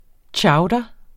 Udtale [ ˈtjɑwdʌ ]